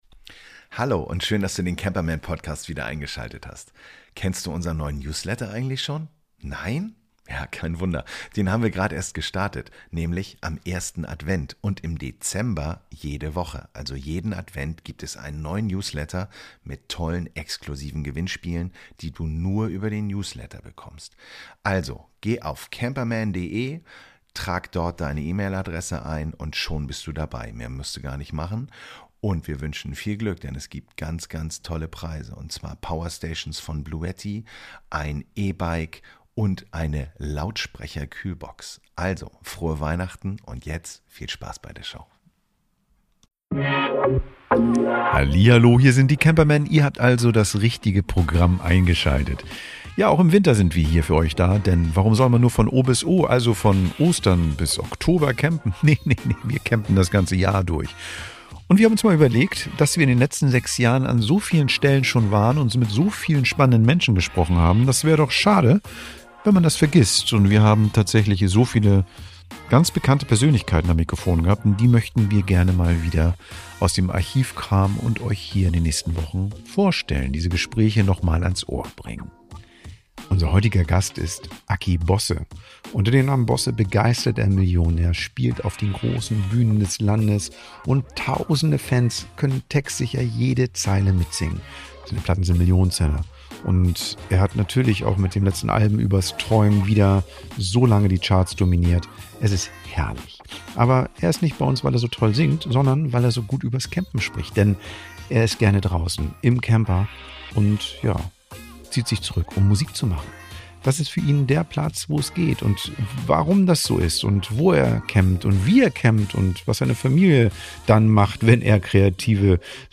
In dieser Folge unserer "VIP-Extras" veröffentlichen wir unser Interview mit dem Sänger Axel "Aki" Bosse.